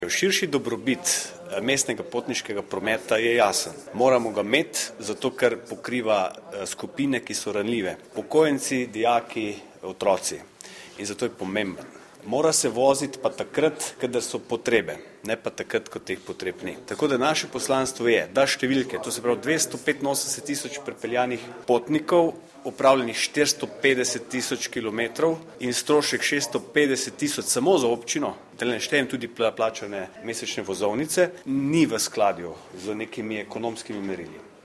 Novo mesto, 20. januar 2015 - Župan Mestne občine Novo mesto Gregor Macedoni je na današnji dopoldanski novinarski konferenci predstavil delo Mestne občine Novo mesto v zadnjem mesecu ter izpostavil ključne korake, ki jih je mestna občina storila pri posameznih aktualnih projektih.